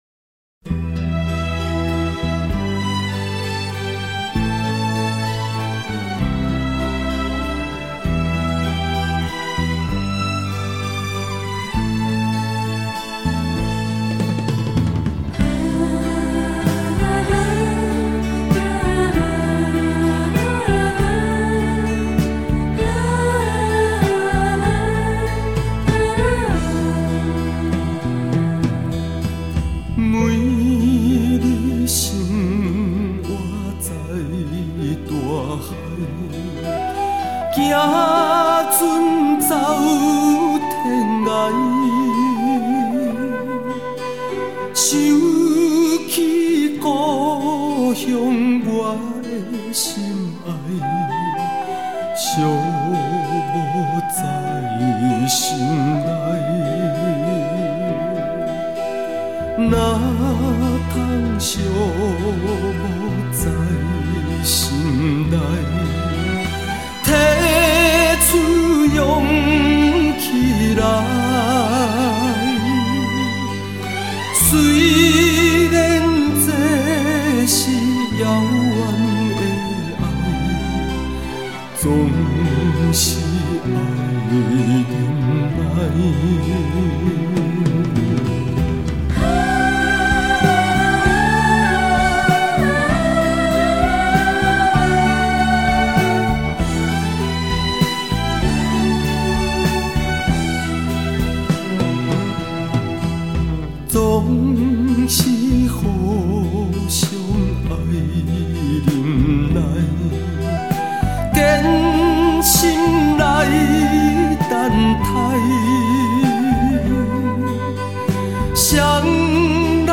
清新浪漫的风格 开启方言聆赏的另扇窗口
浑厚磁性的嗓音 开创台语歌曲优质化的先河